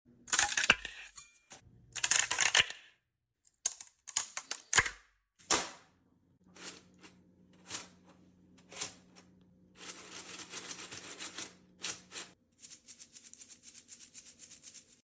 3d printed flexisqueeze fidget toy sound effects free download
3d printed flexisqueeze fidget toy asmr!